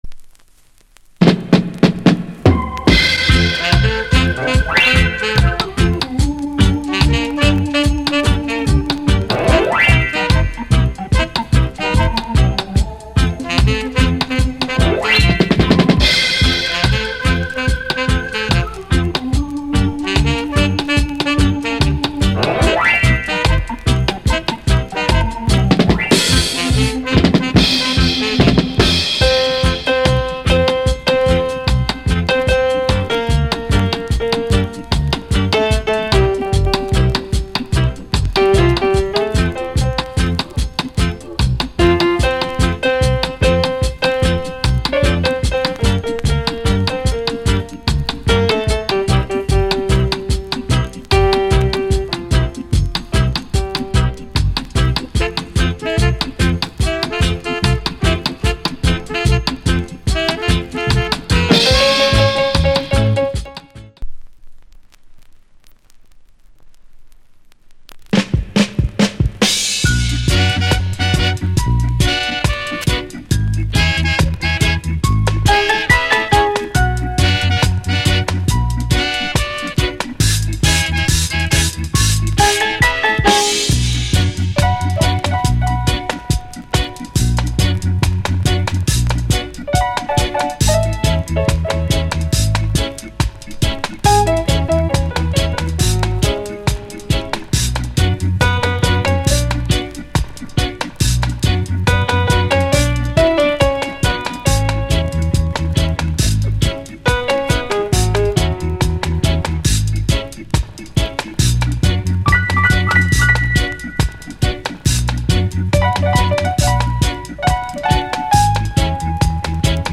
Sweet Piano Inst